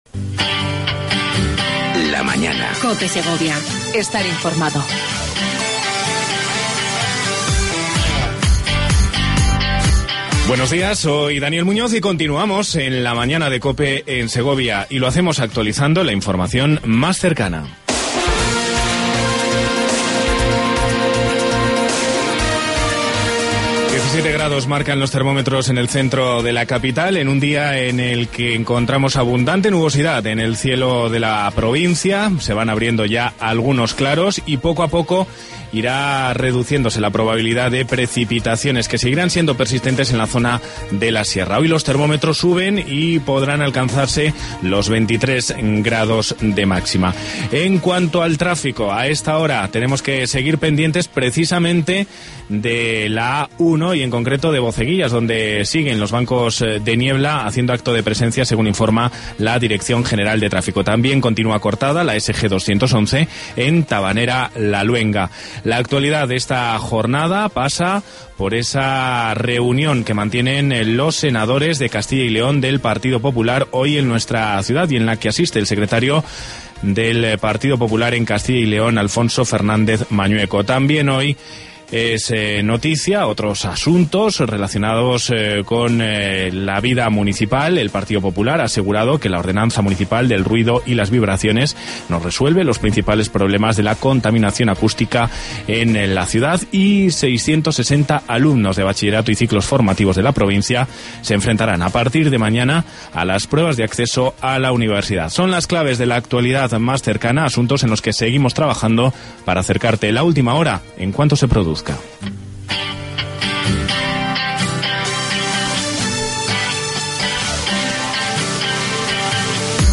AUDIO: Entrevista a Pedro Arahuetes, Alcalde de Segovia.